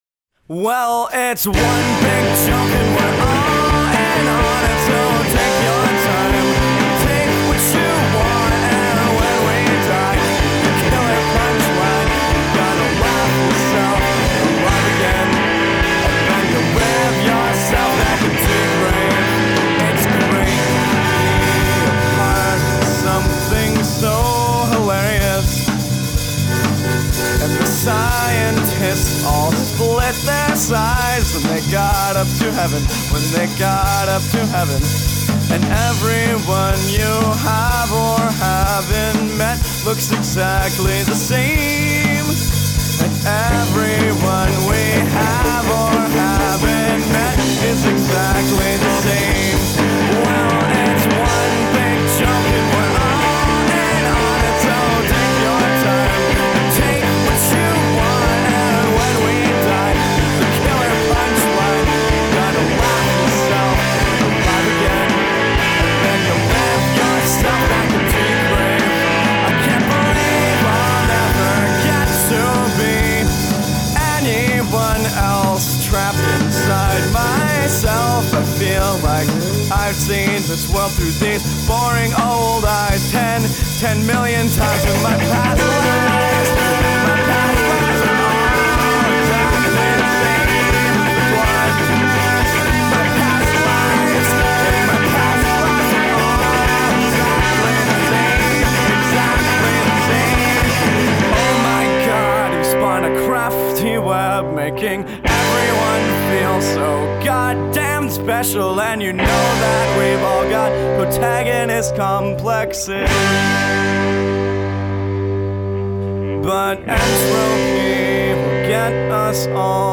the saviors of jangly noodly punk rock.